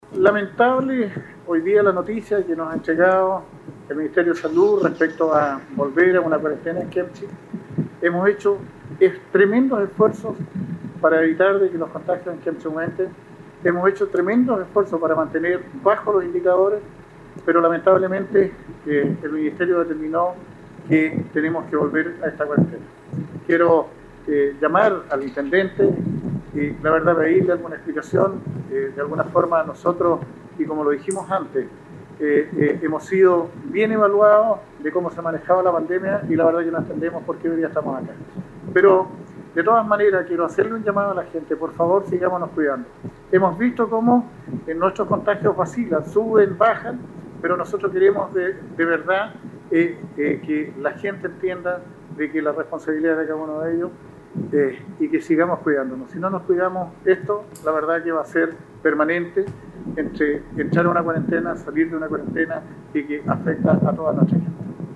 El alcalde Gustavo Lobos lamentó la determinación y llamó al intendente de Los Lagos Carlos Geisse a entregar una “explicación” acerca de por qué se tomó esta decisión de hacer recaer nuevamente a la comuna en confinamiento.